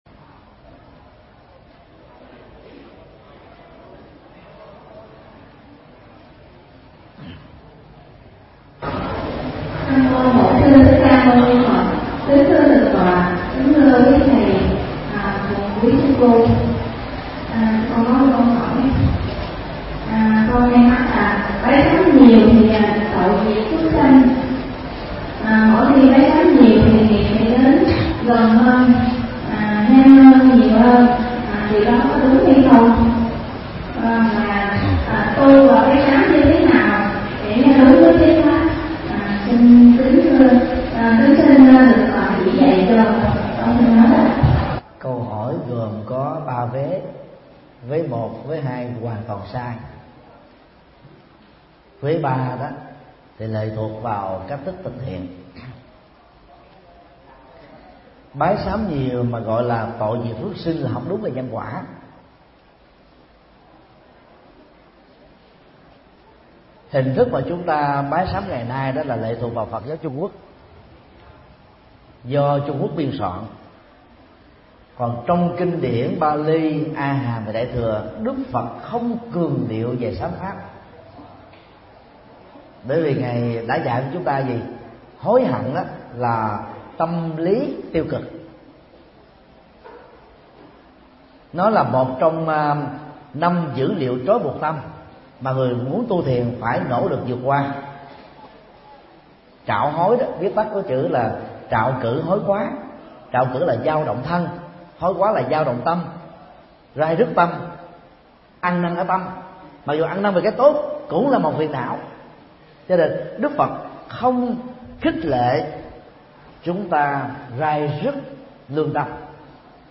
Vấn đáp: Tu tập bái xám – thầy Thích Nhật Từ mp3